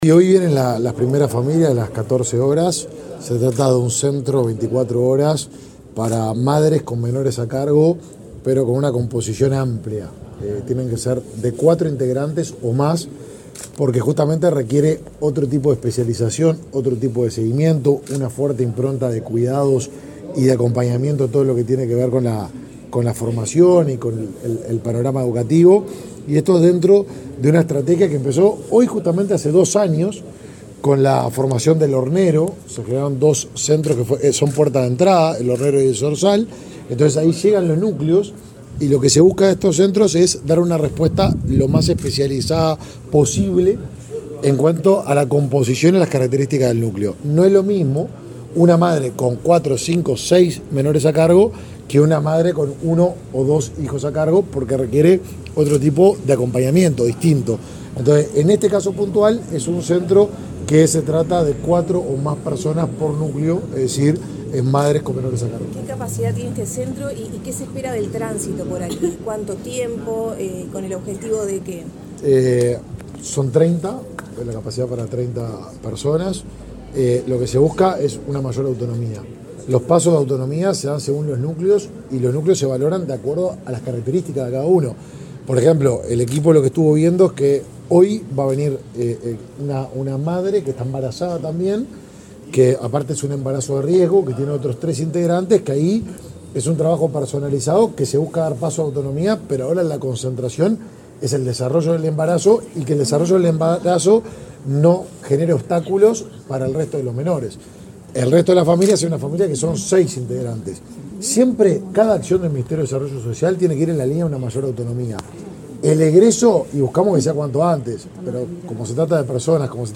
Declaraciones del ministro de Desarrollo Social, Martín Lema
Luego dialogó con la prensa sobre las características del servicio.